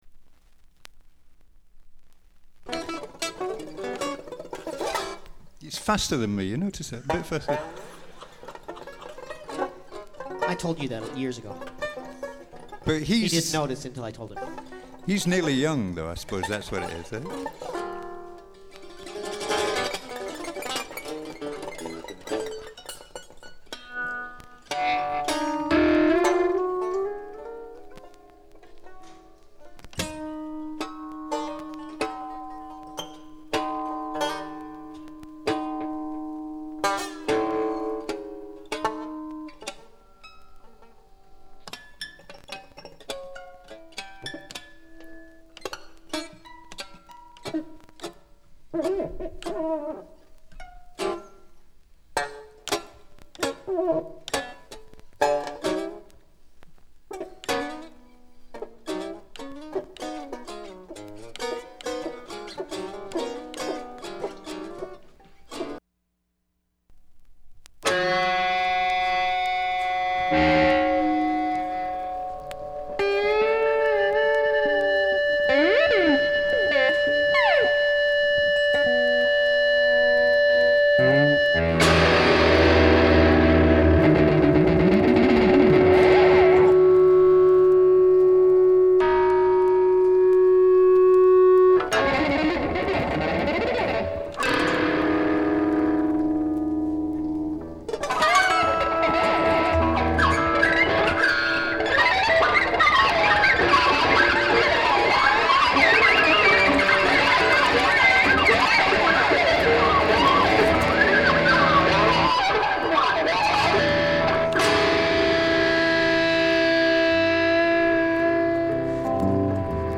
discription:Stereo